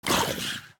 Minecraft Version Minecraft Version snapshot Latest Release | Latest Snapshot snapshot / assets / minecraft / sounds / mob / husk / hurt1.ogg Compare With Compare With Latest Release | Latest Snapshot
hurt1.ogg